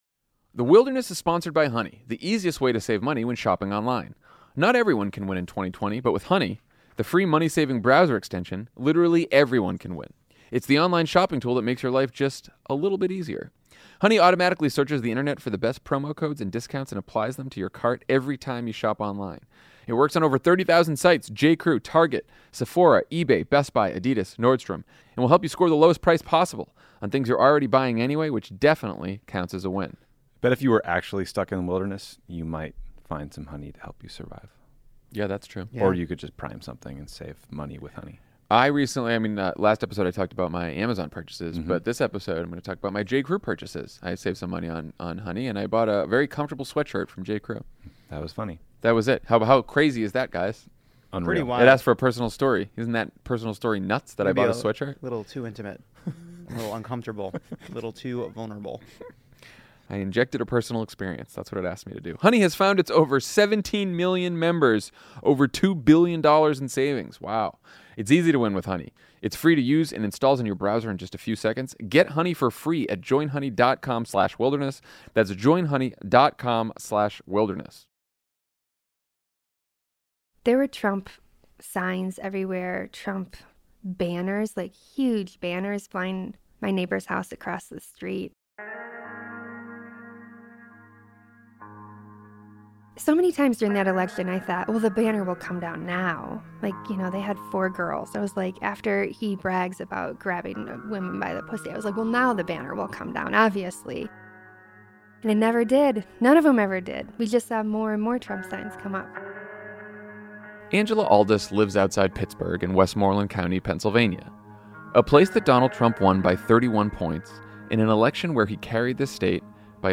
How can grassroots organizers flip a red state? We talk to women who are trying to turn Pennsylvania blue and defeat Susan Collins, and sit down with a focus group of disaffected Democrats outside of Philadelphia.